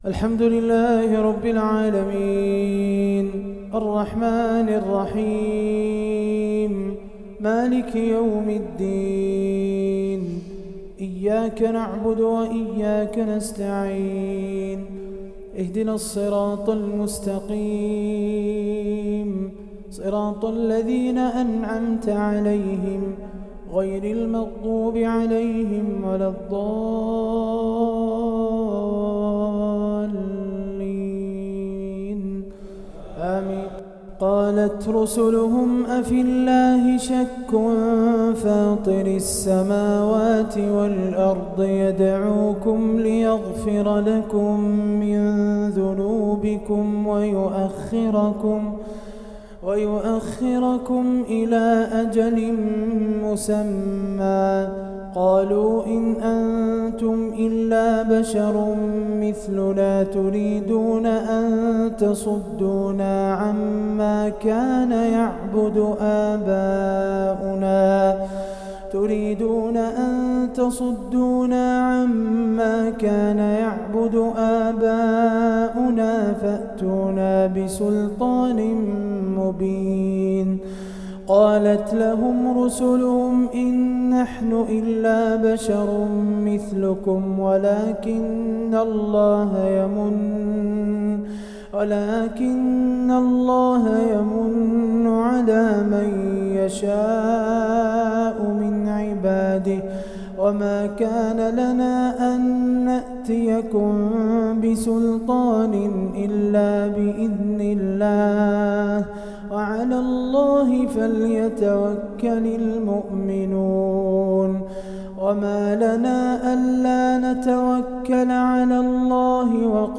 تلاوة خاشعة